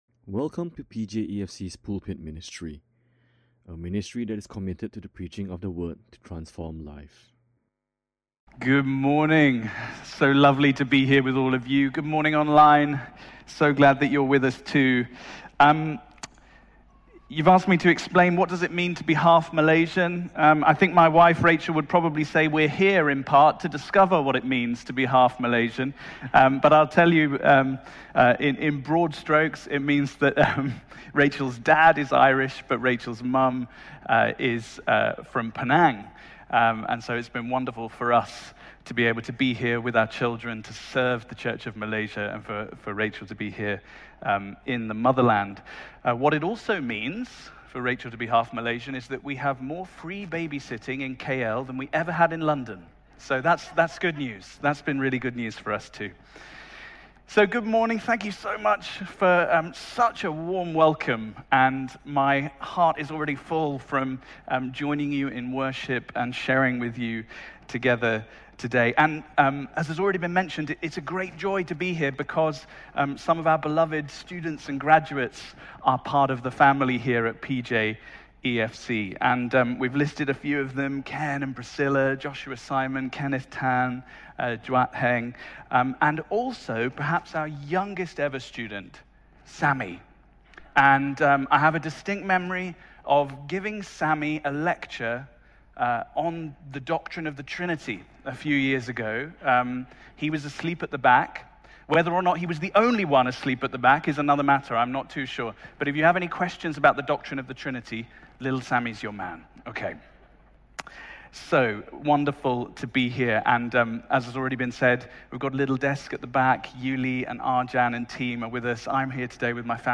May 1, 2022 A stand-alone sermon on rest.
Listen to Sermon Only